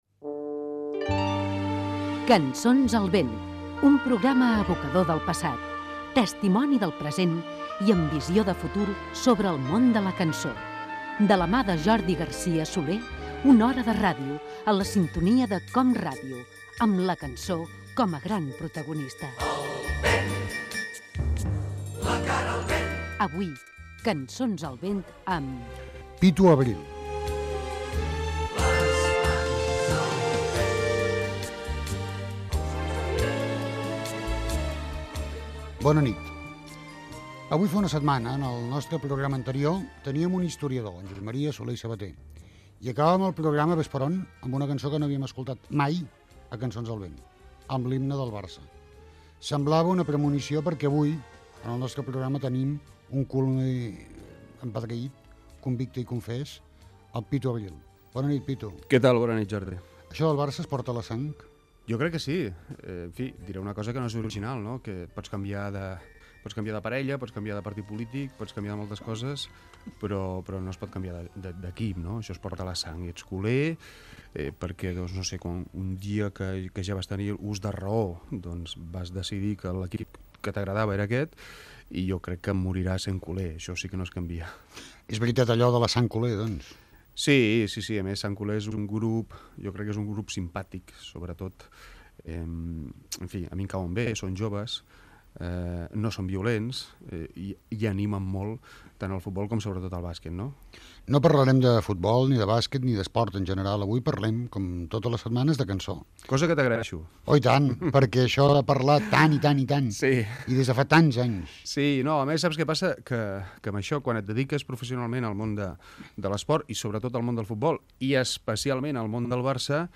Careta, presentació i entrevista al periodista
Divulgació